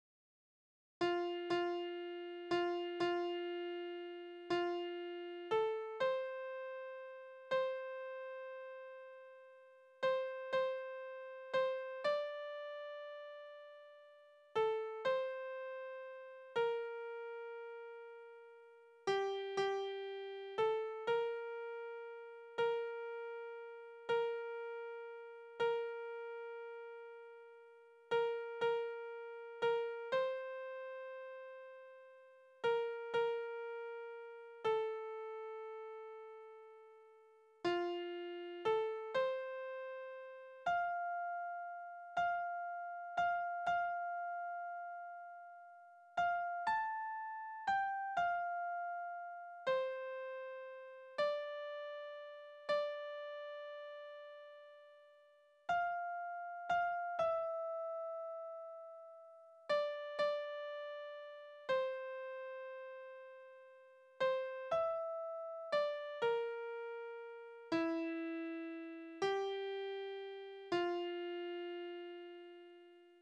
Tonart: F-Dur Taktart: 3/4 Tonumfang: Oktave, Quarte Besetzung: instrumental